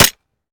weap_golf21_fire_last_plr_mech_01.ogg